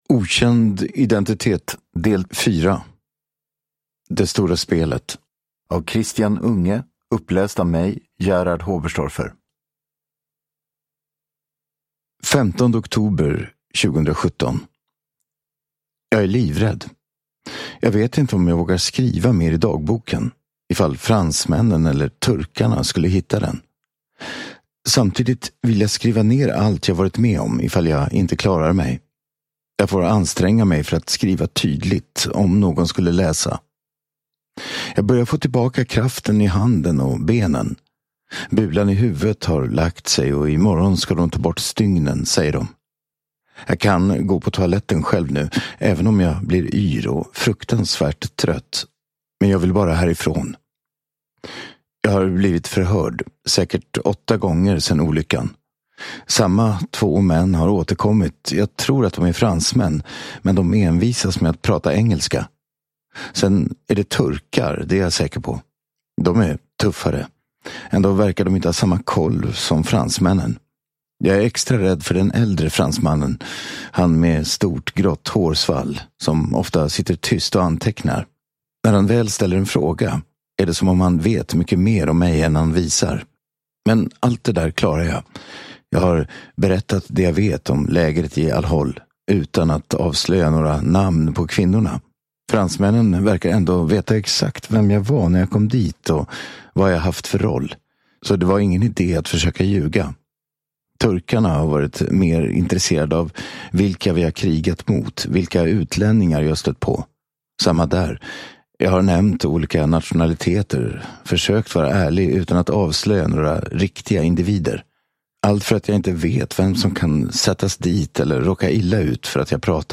Det stora spelet (ljudbok) av Christian Unge